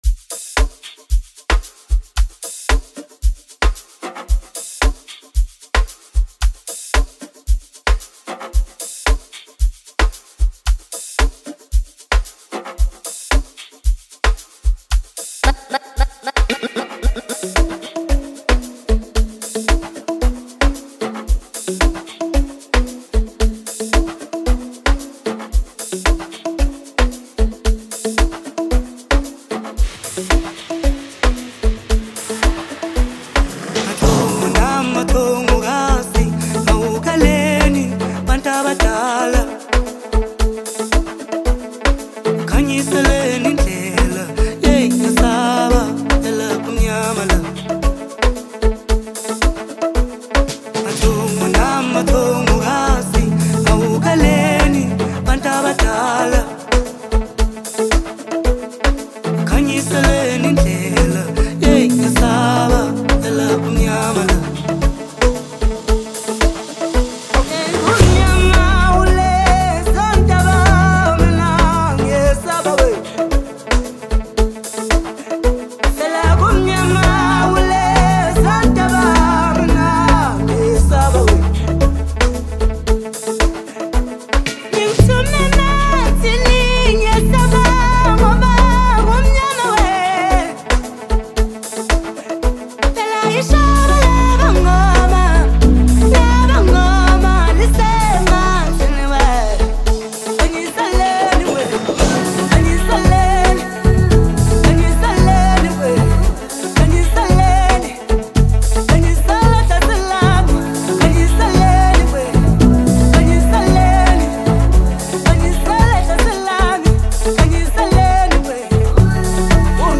mellow vocal fusion that accentuates the entrancing melody.